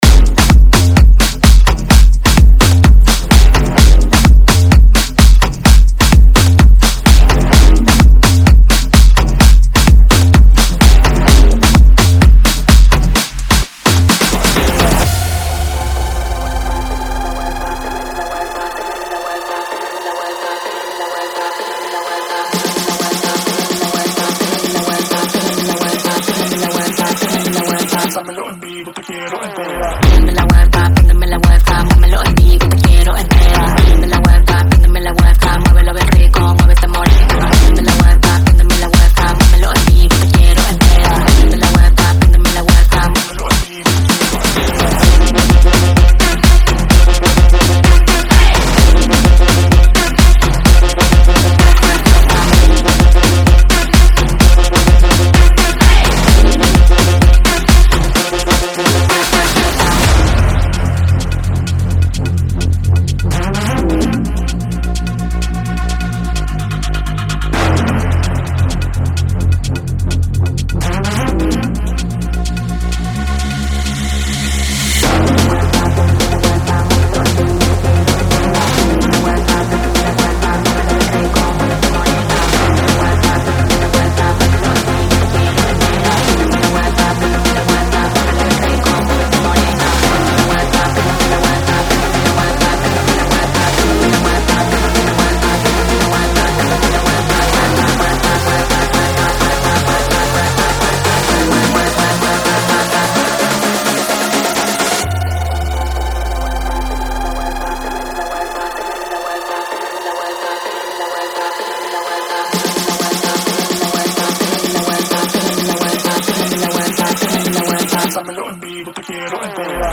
Genre: RE-DRUM Version: Clean BPM: 96 Time